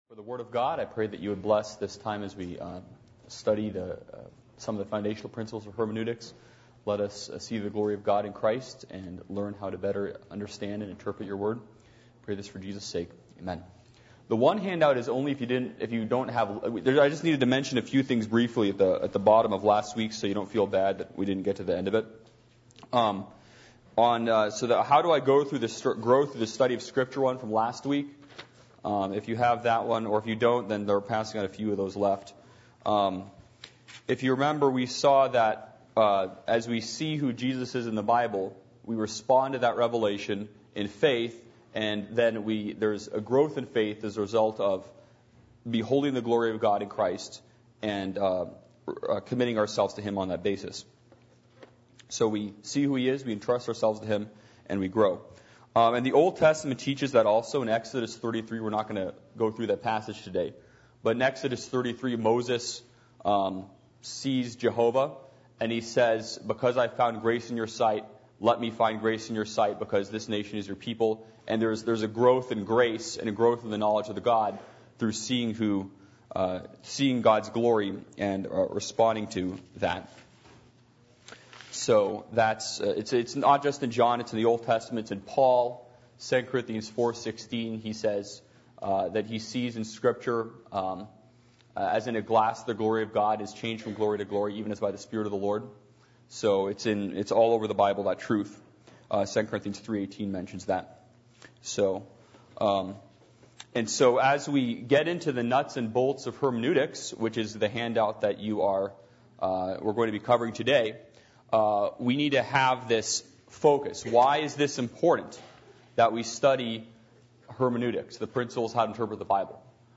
How To Interpret The Bible Service Type: Adult Sunday School %todo_render% « An Independent New Testament Church Is Complete In Itself Philadelphia